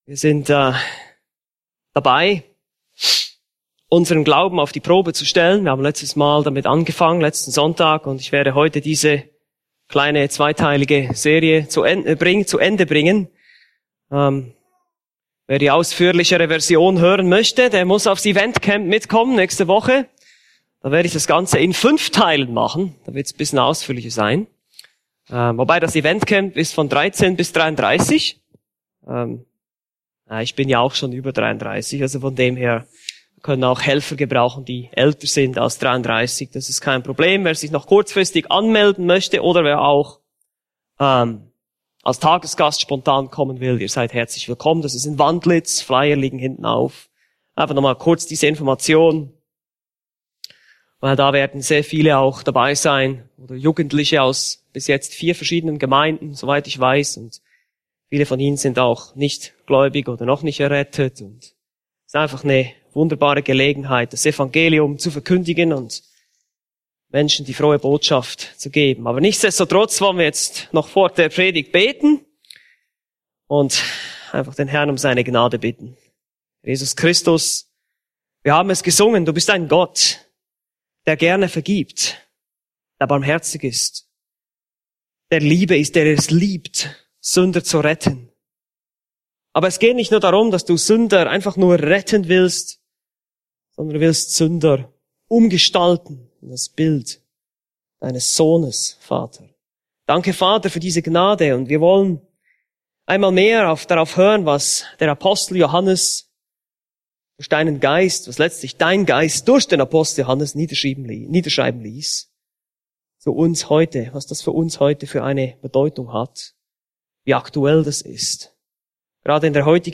Eine predigt aus der serie "Weitere Predigten."